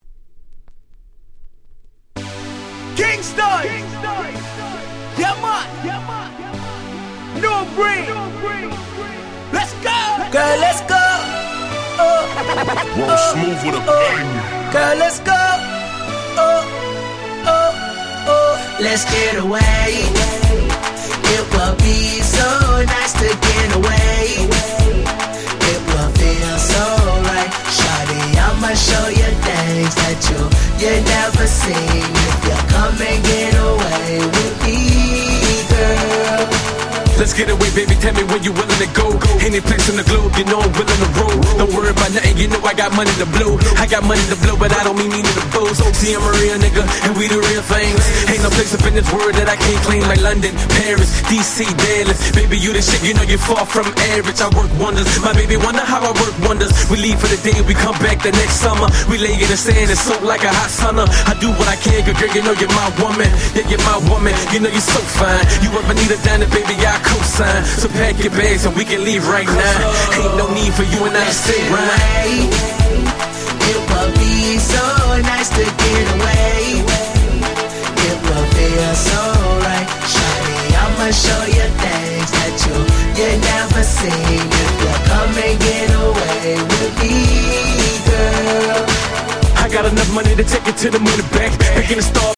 10' Smash Hit R&B !!